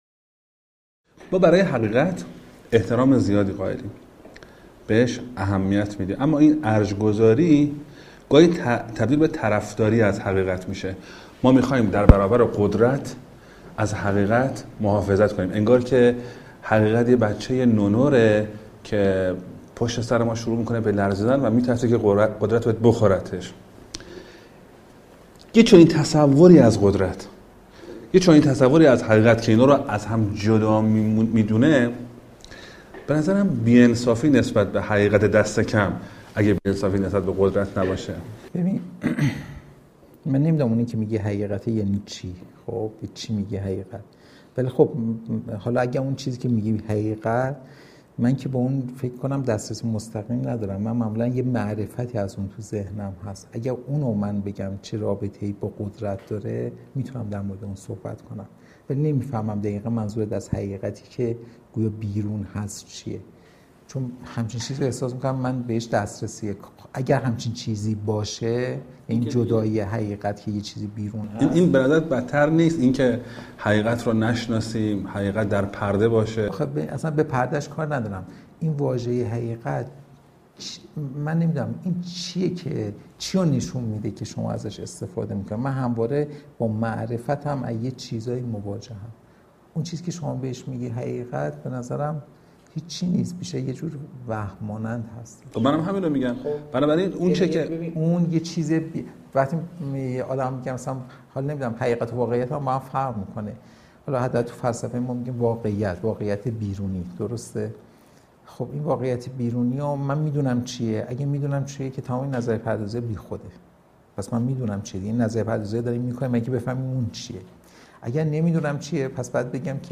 گفت و گوی سیما فکر